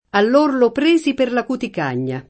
cuticagna [ kutik # n’n’a ]